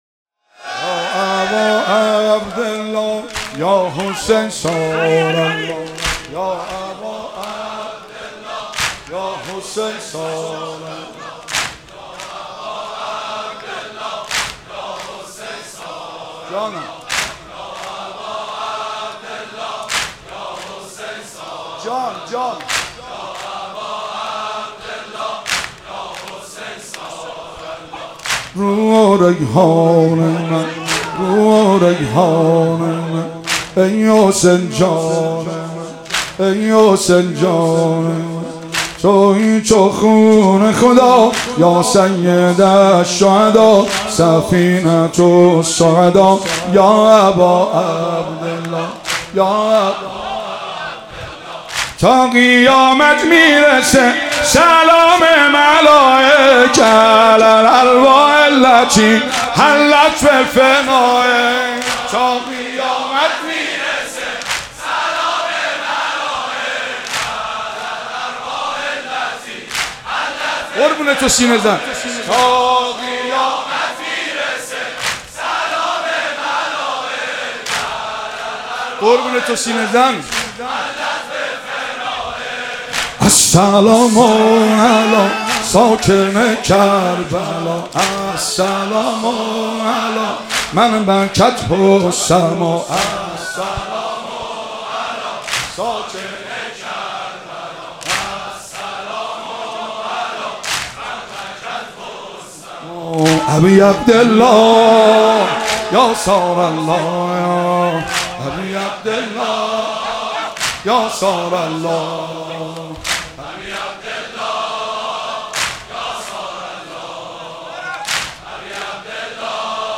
مراسم شب سوم محرم الحرام سال 1395